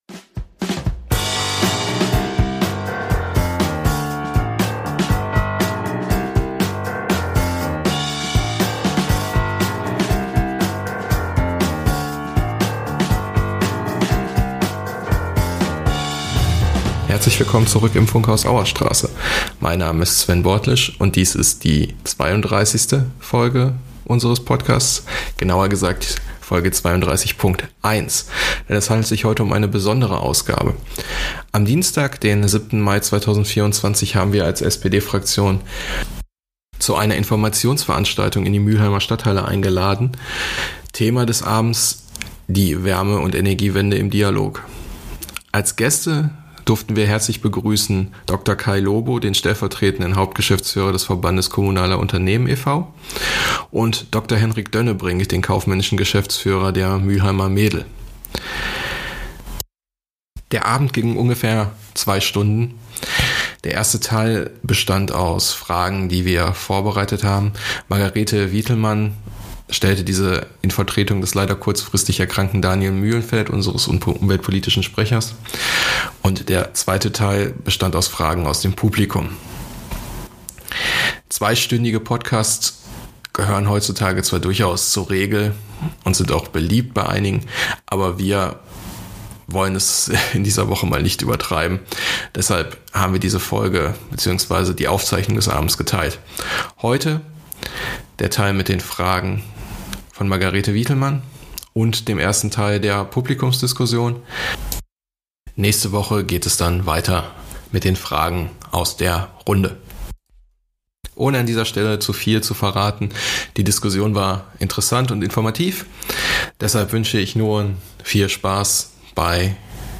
Natürlich haben wir die Veranstaltung aufgezeichnet.